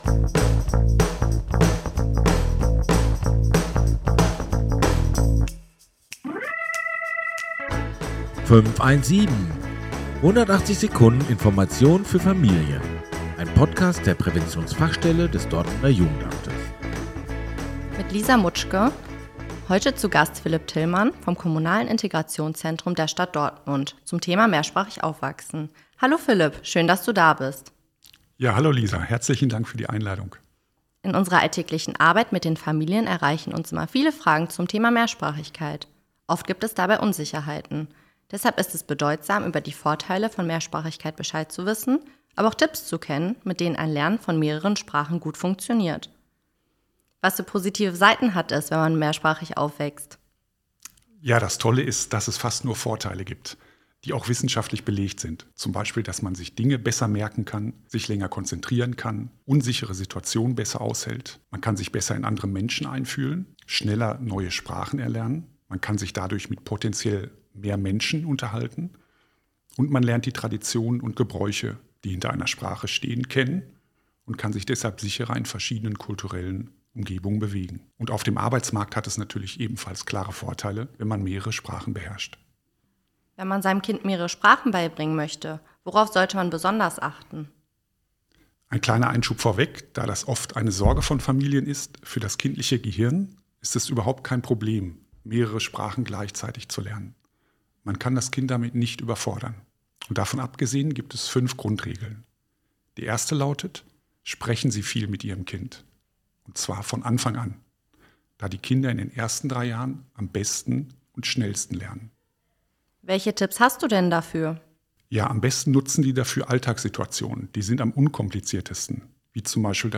In rund 180 Sekunden sind zu Themen wie der Willkommensbesuch, die Arbeit einer Hebamme oder Mehrsprachigkeit interessante Menschen zu Gast, die im Gespräch mit Fachkräften der Präventionsfachstelle Einblicke in ihre Arbeit bzw. ihre Themen geben.